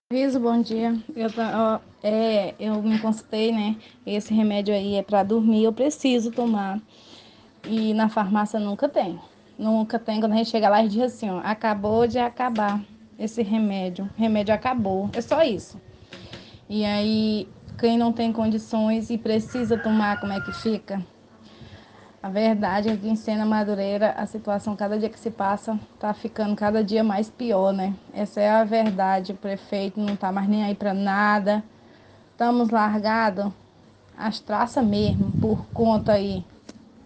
Uma moradora de Sena Madureira denuncia a falta de medicamentos na farmácia municipal. A mulher relata que procurou o remédio que precisa para dormir, mas foi informada que o mesmo estava em falta.